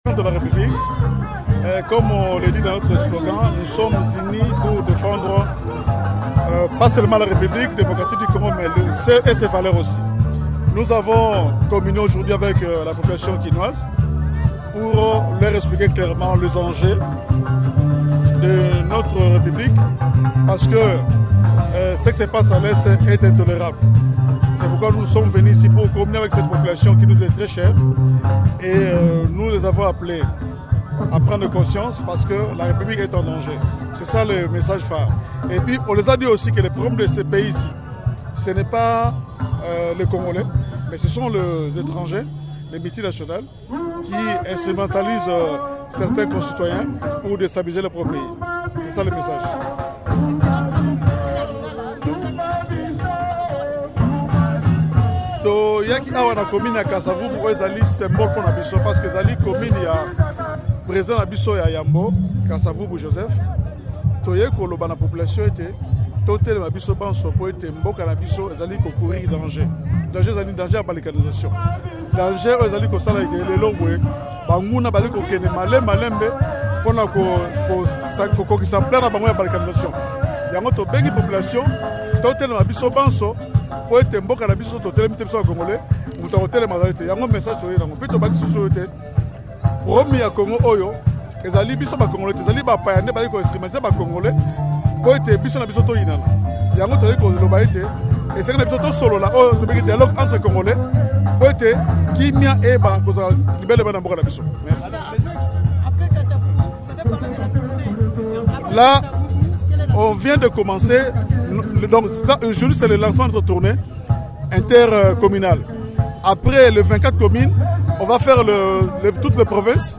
Le Camp de la République mobilise pour le dialogue national dans un meeting populaire à Kinshasa
Après les bureaux climatisés et salons huppés, le Camp de la République a choisi, samedi 27 septembre, la place publique pour poursuivre sa campagne en faveur du dialogue national.